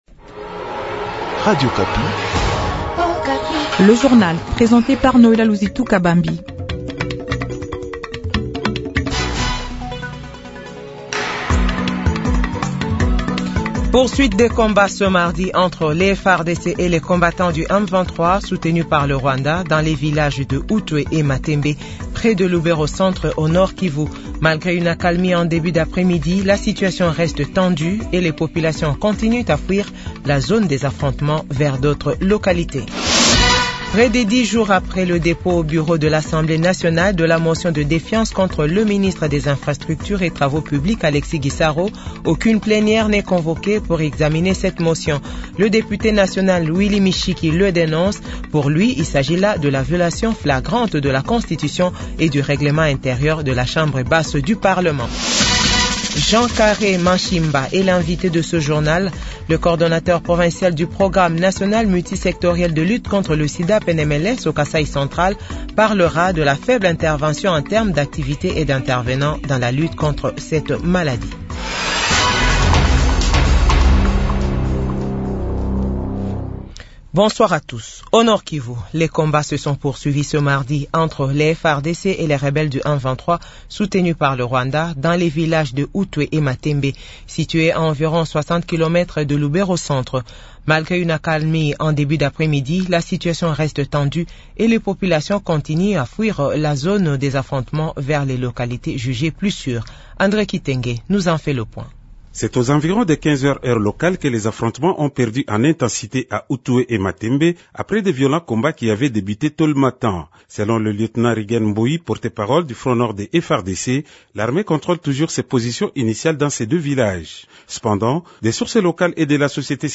JOURNAL FRANÇAIS DE 18H00